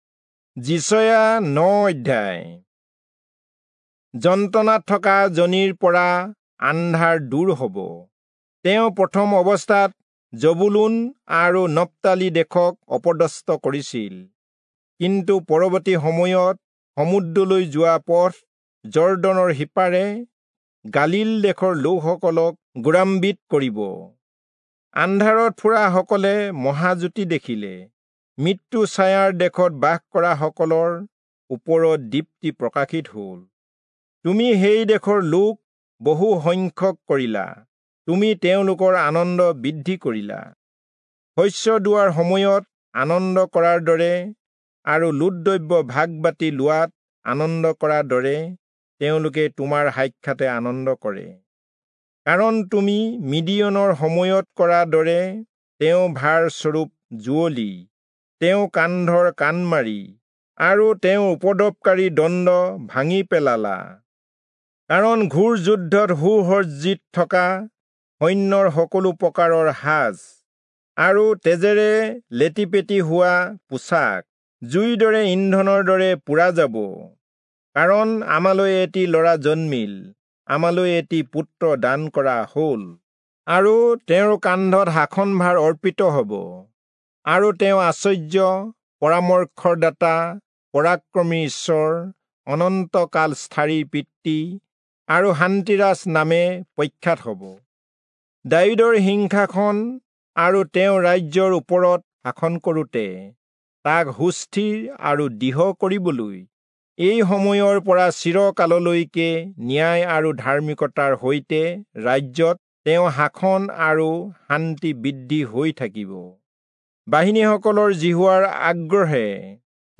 Assamese Audio Bible - Isaiah 6 in Wlc bible version